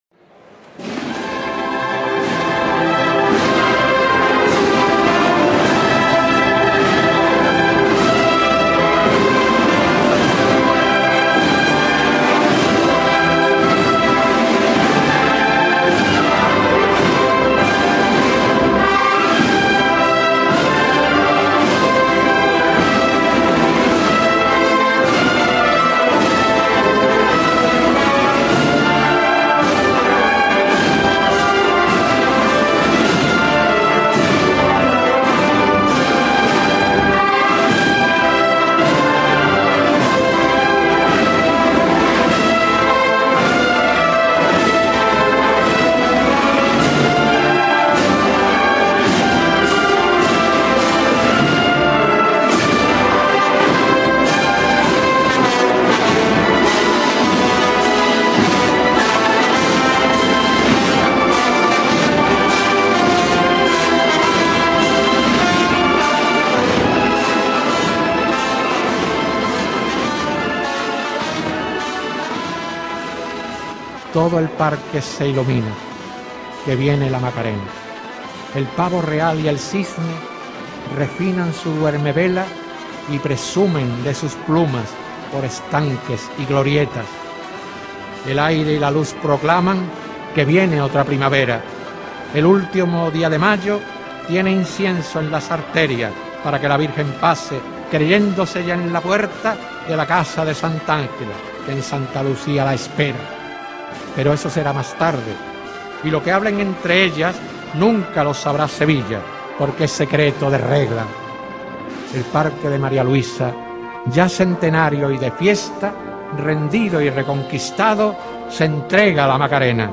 una entrega semanal de sevillanas para la historia.
Temática: Cofrade